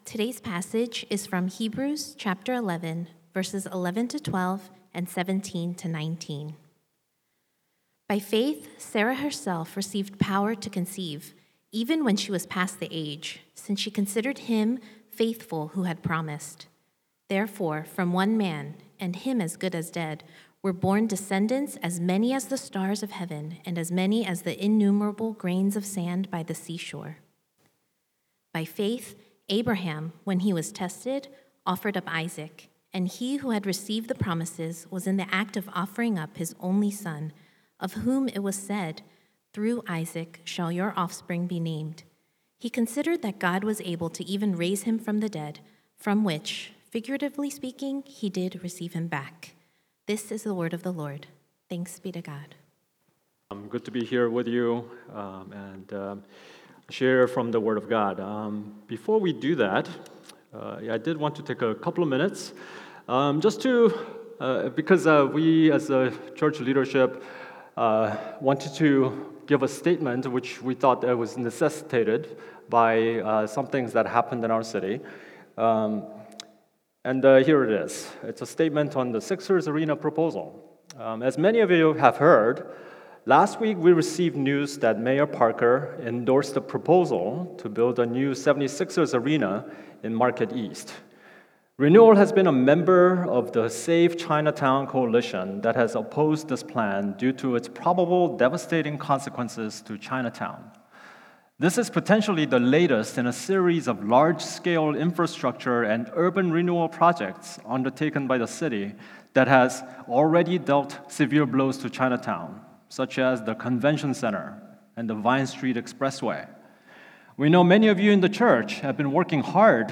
A message from the series "By Faith (CC)."